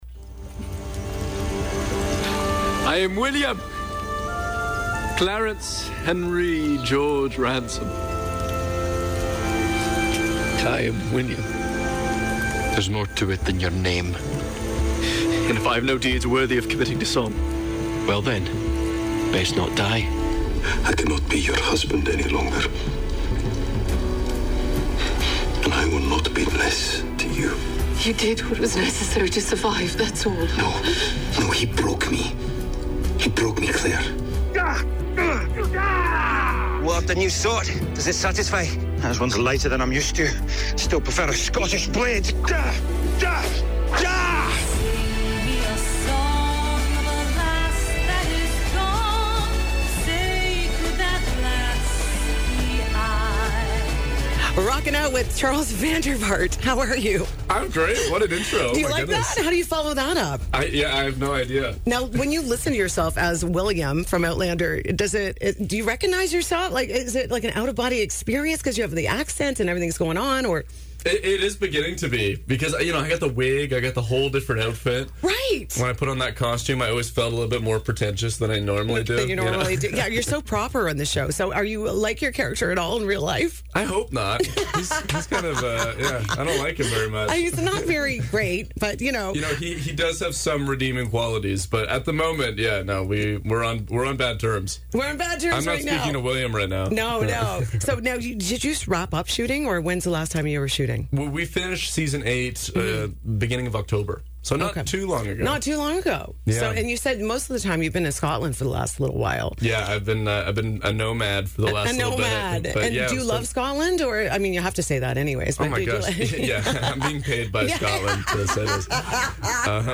INTERVIEW: Charles Vandervaart from Outlander Visits The Grand at 101
Charles Vandervaart stopped by The Grand at 101 on Friday morning ahead of this year’s Fergus Scottish Festival.
charles-interview.mp3